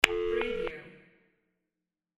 Phone Call Disconnect Tone Wav Sound
Description: The sound of a phone call being disconnected and transferred (tone)
Keywords: phone, line, call, disconnect, open, beep, transfer, tone
phone-call-disconnect-tone-preview-1.mp3